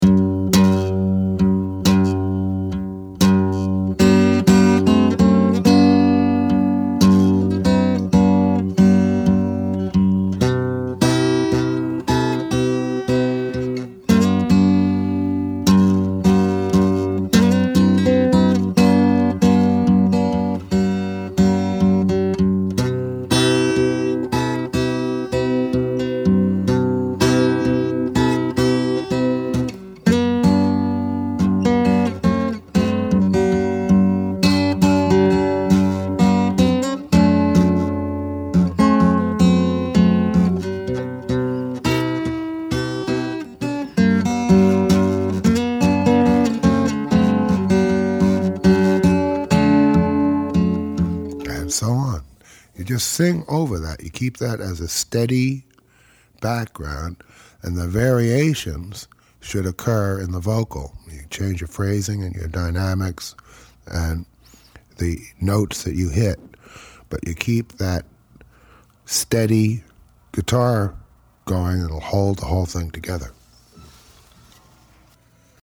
fingerstyle guitarist
blues and a classic rag
and 3 CDs teaching each tune phrase by phrase.